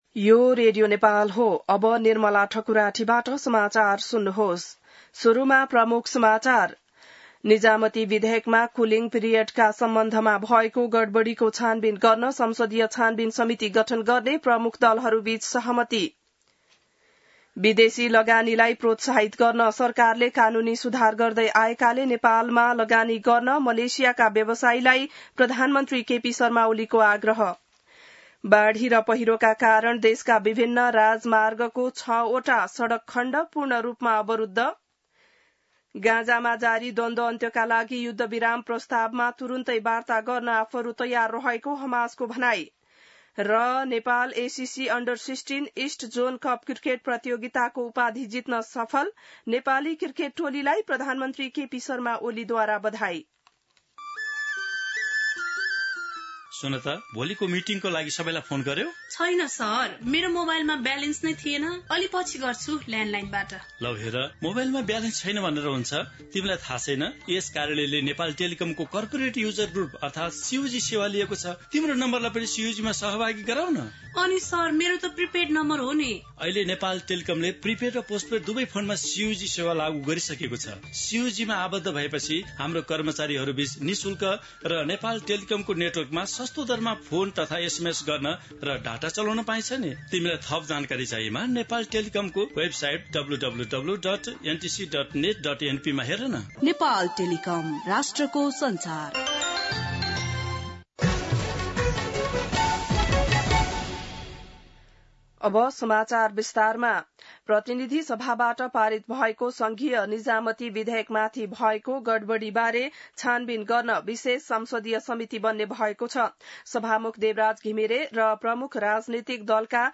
बिहान ७ बजेको नेपाली समाचार : २४ असार , २०८२